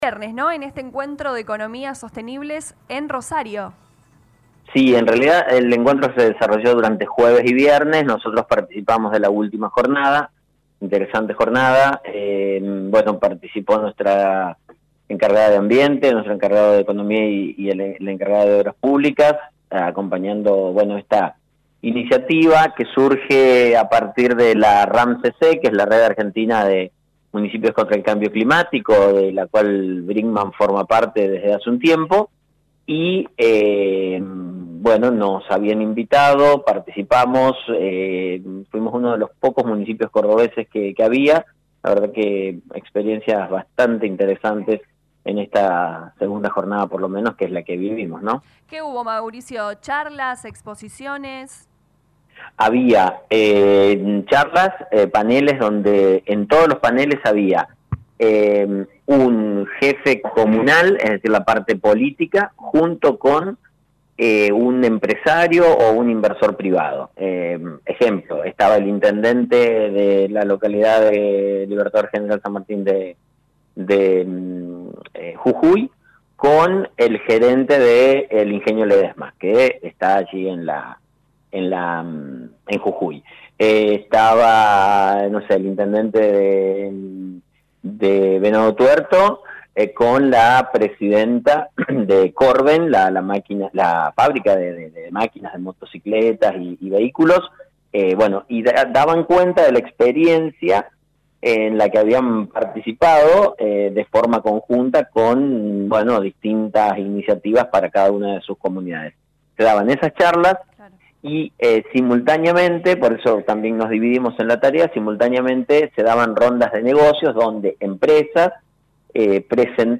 El intendente Municipal Lic. Mauricio Actis dialogó con LA RADIO 102.9 FM y contó que junto a funcionarios estuvieron presentes en el primer encuentro de Economías Sostenibles desarrollado en la ciudad de Rosario.